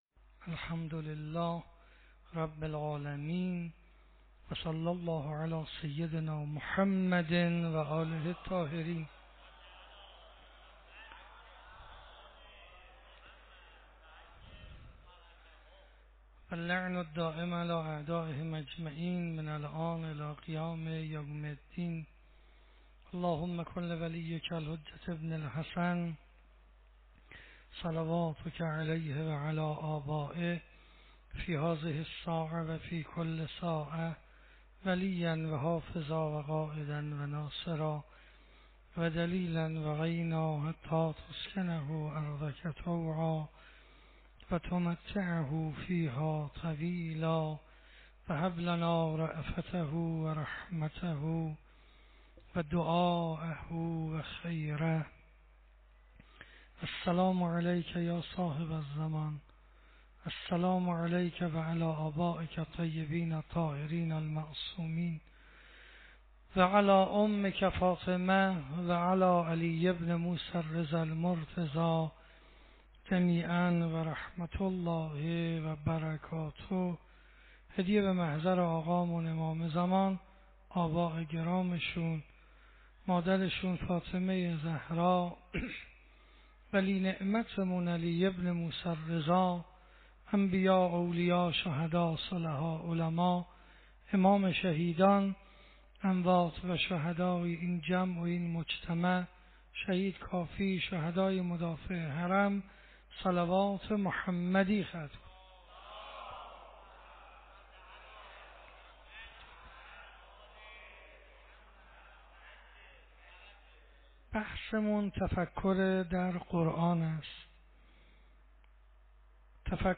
شب 12 ماه مبارک رمضان 96 - مهدیه تهران